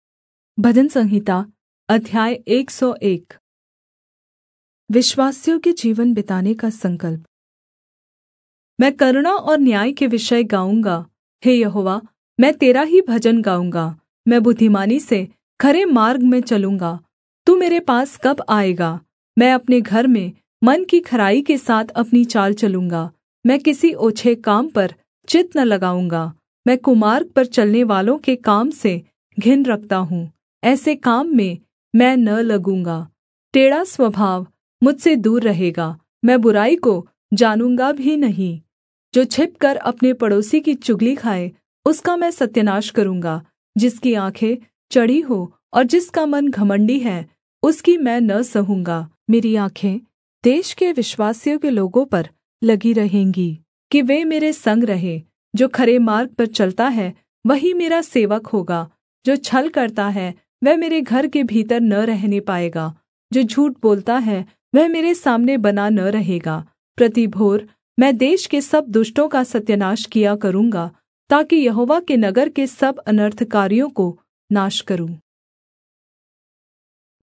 Hindi Audio Bible - Psalms 123 in Irvhi bible version